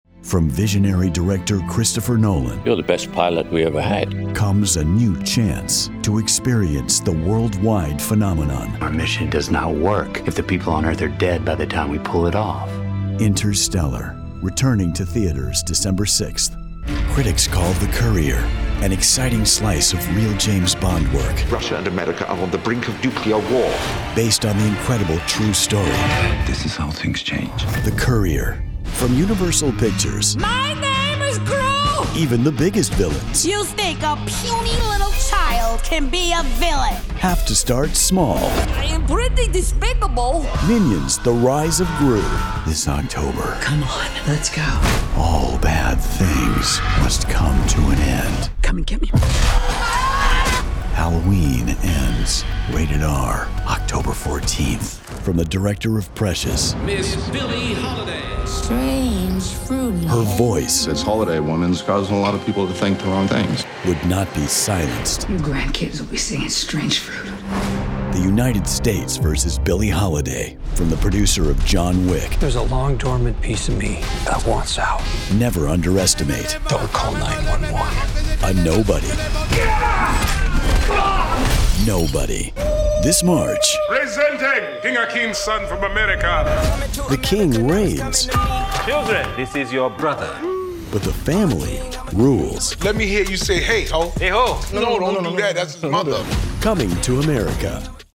Trailers
Middle Aged
Microphone: Sennheiser MKH416
Acoustically treated home recording space